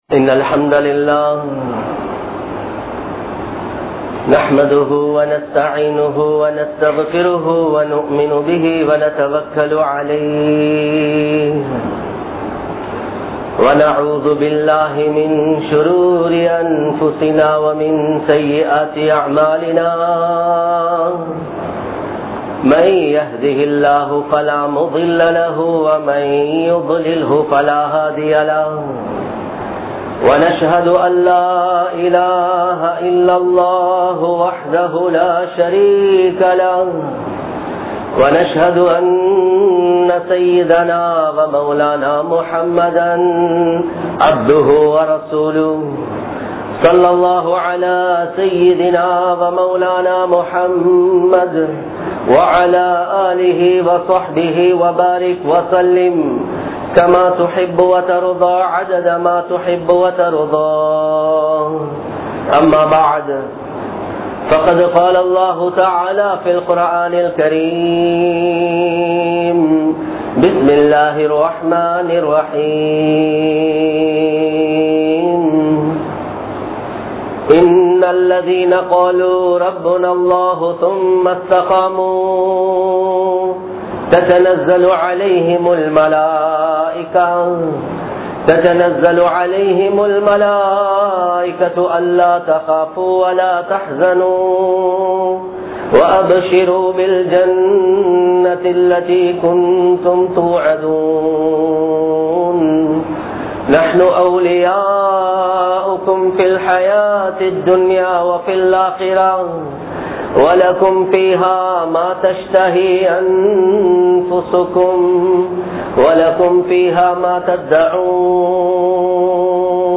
Islamiya Paarvaiel Nithi (இஸ்லாமிய பார்வையில் நிதி) | Audio Bayans | All Ceylon Muslim Youth Community | Addalaichenai